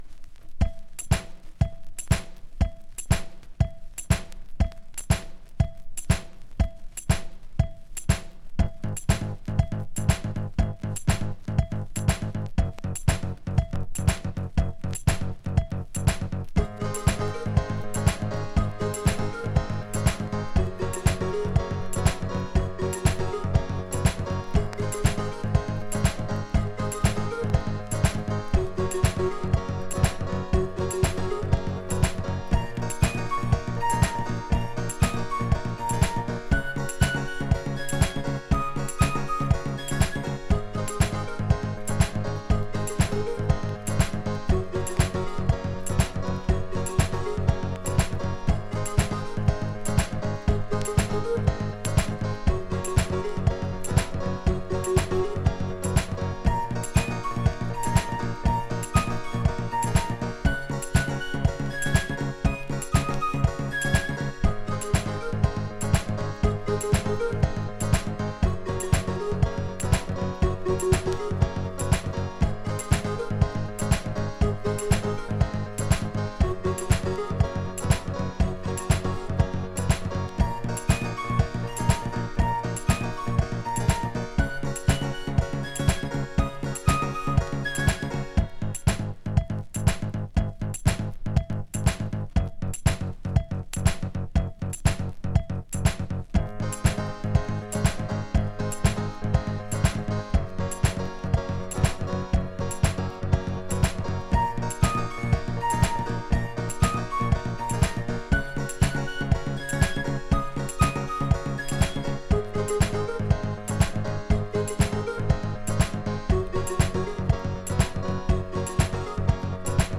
Balearic Classic!
バレアリック古典ナンバーとしてもお馴染みの哀愁系A.O.R.！
【A.O.R.】【DISCO】